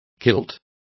Complete with pronunciation of the translation of kilts.